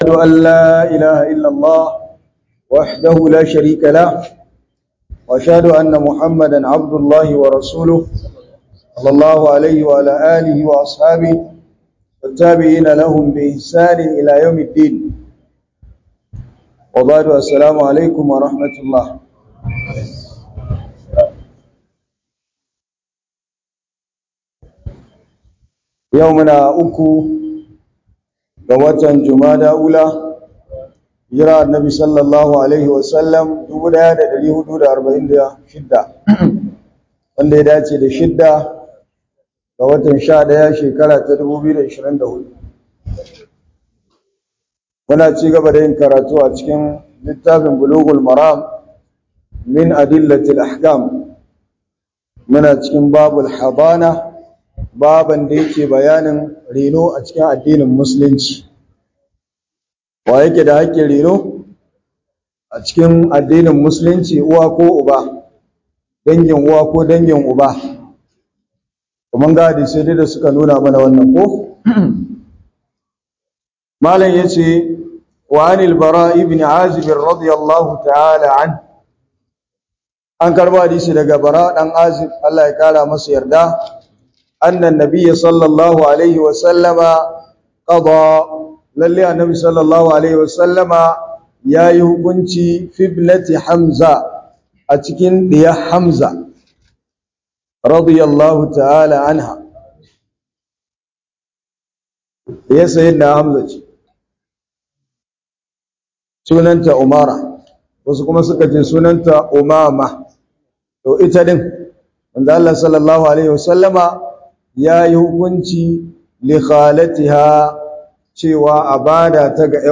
hudubar Juma'a 15 Novembre 2024